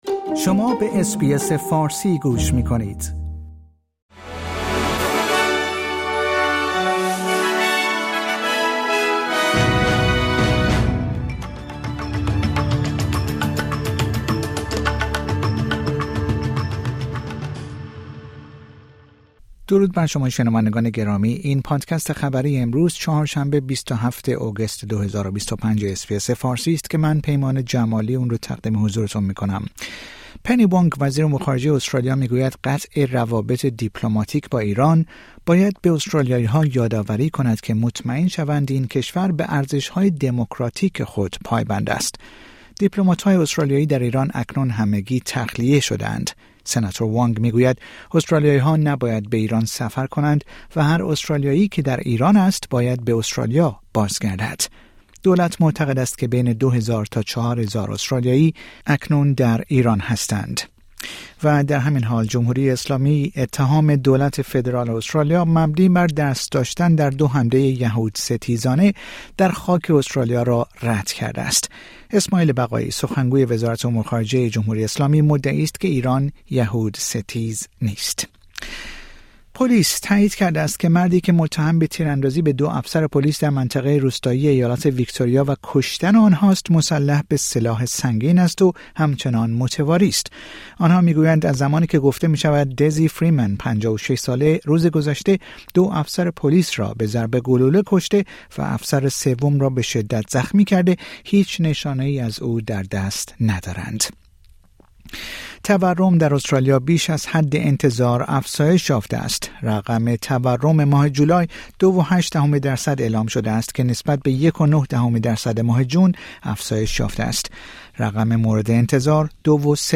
در این پادکست خبری مهمترین اخبار روز چهارشنبه ۲۷ آگوست ارائه شده است.